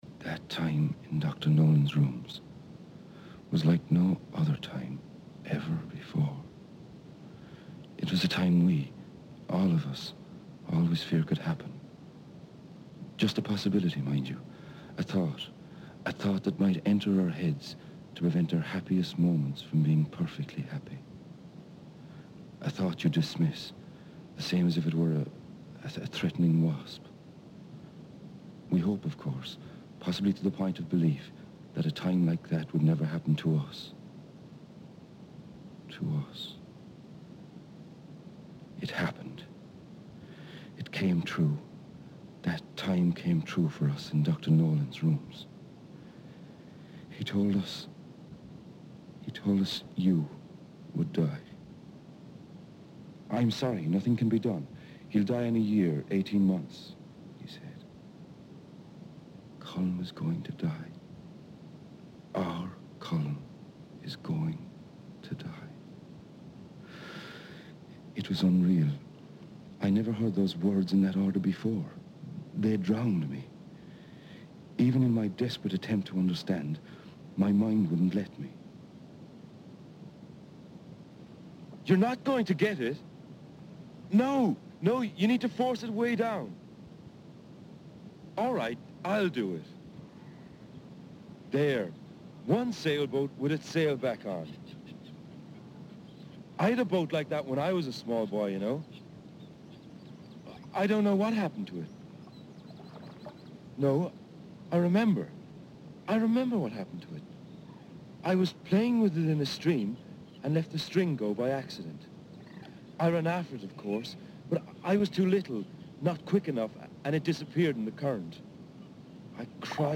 Radio plays produced by R.T.E. "Fools Day Robber" " The String " Winner of the PJ O' Connor Radio Award.